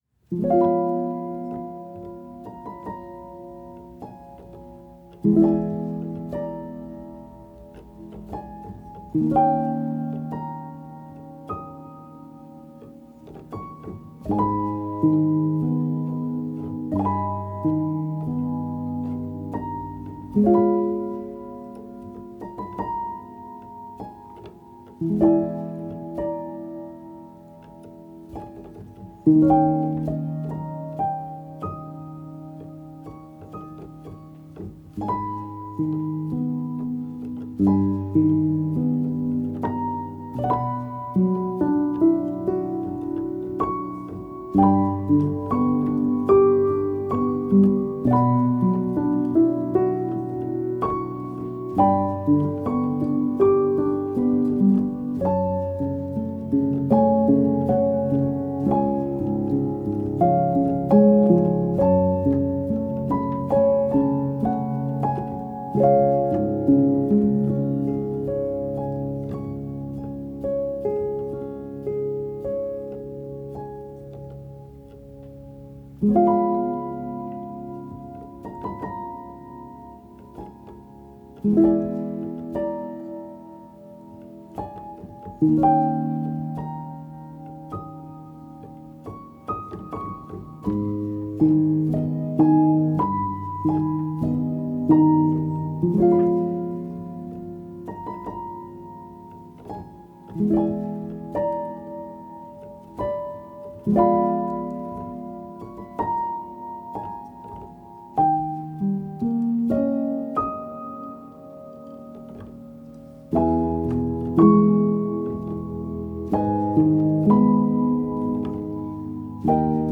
is a modern classical solo piano composition.